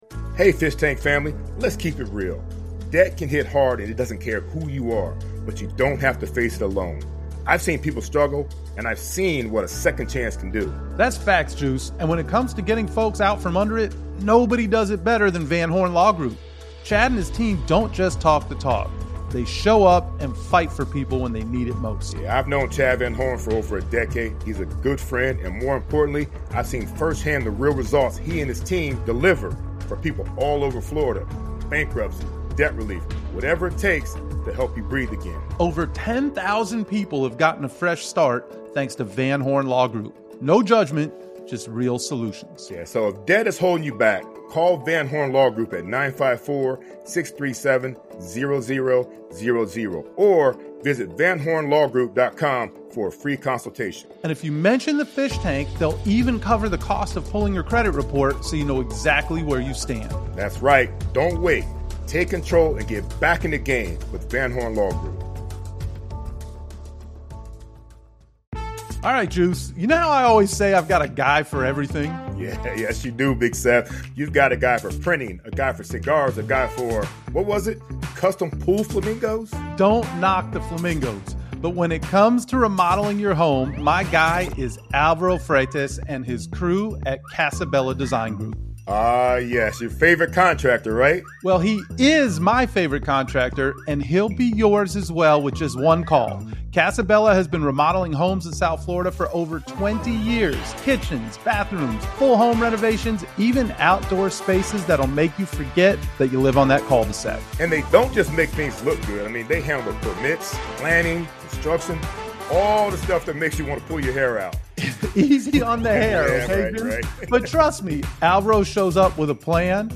Fish Tank LIVE officially debuts on the DolphinsTalk Network, and the timing couldn’t be bigger.